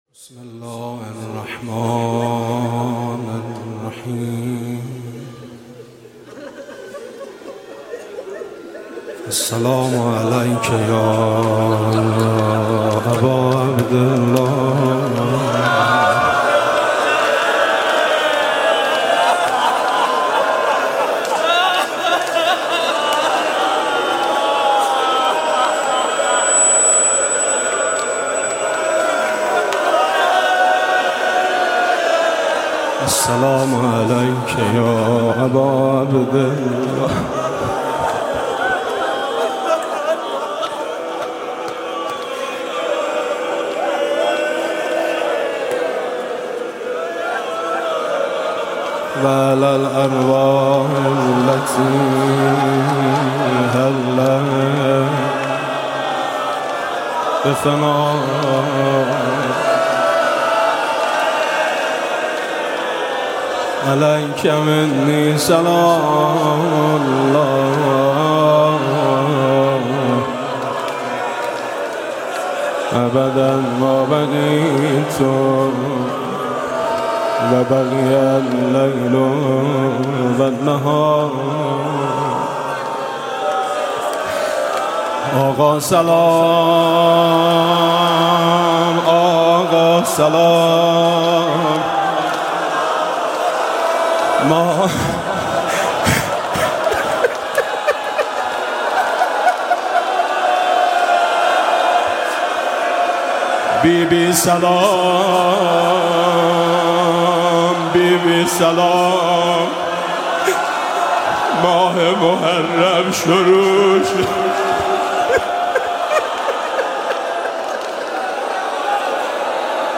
روضه بی بی سلام با مداحی میثم مطیعی در شب اول محرم 92
روضه "بی بی سلام" با مداحی حاج میثم مطیعی در شب اول محرم 1392 شــ